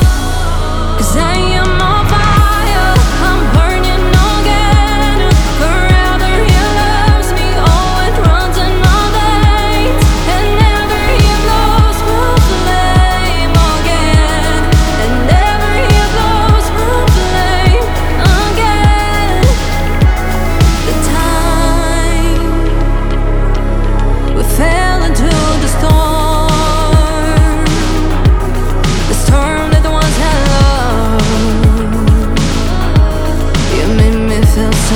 Модульные синтезаторы и глитчи
создают футуристичный саунд
Pop Electronic
Жанр: Поп музыка / Электроника